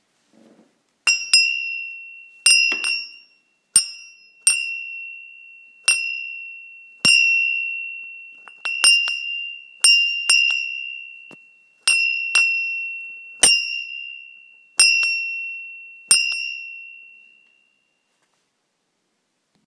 Zvonek
Litinový zvonek s krásným zvukem je dokonalým doplňkem v chalupářském stylu.
Rozměry: 22x12 cm
Materiál: litina